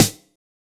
BEAT SD 05.WAV